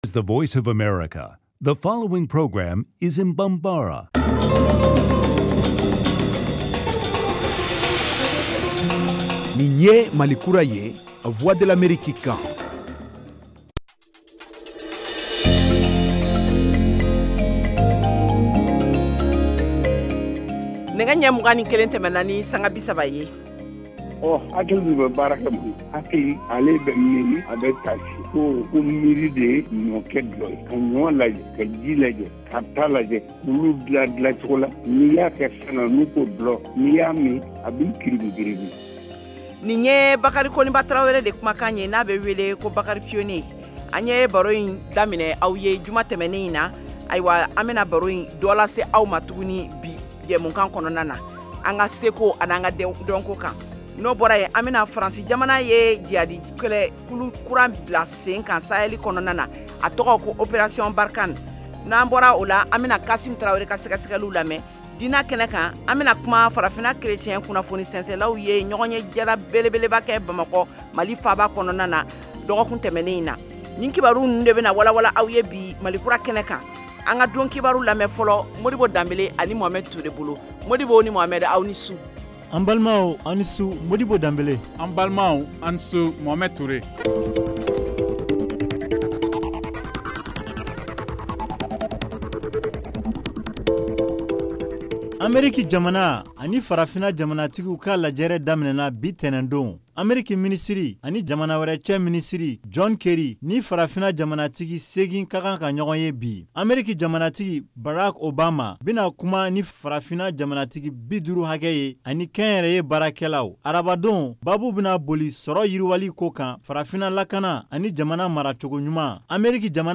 en direct de Washington. Au menu : les nouvelles du Mali, les analyses, le sport et de l’humour.